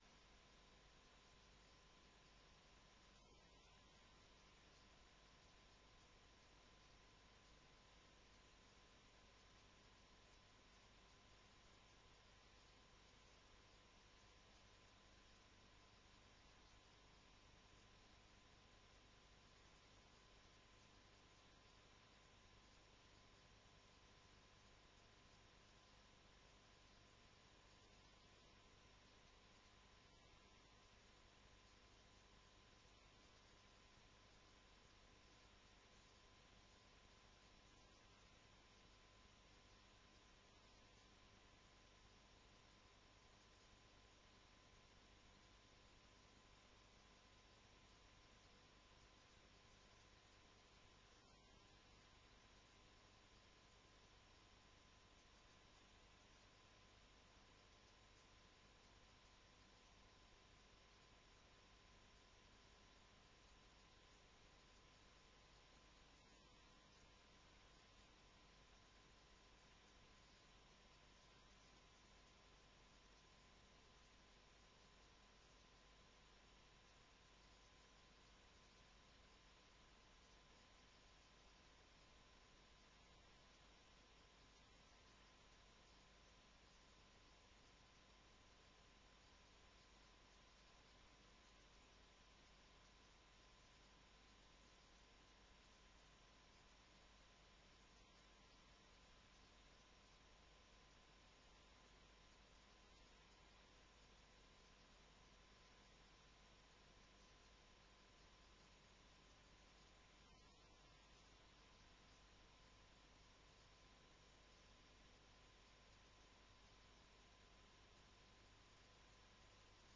Locatie: Raadzaal